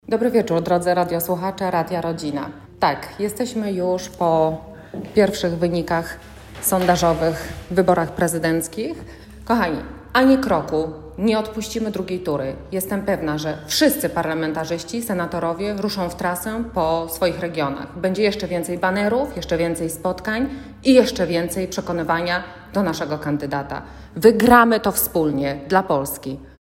Anna Sobolak, posłanka na Sejm (KO) tuż po 21:00 skomentowała nam pierwsze, sondażowe wyniki.
Anna-Sobolak.mp3